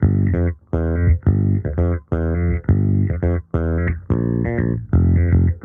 Index of /musicradar/sampled-funk-soul-samples/85bpm/Bass
SSF_JBassProc1_85G.wav